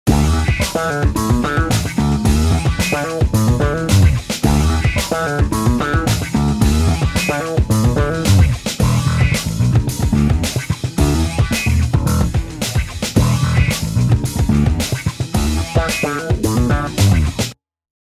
ふつーのロックベースとかと違ってさ
とにかく、ライン動く動く！うねるうねる！
こーゆーの 弾くとさ自然と踊りたくなってくるしっ！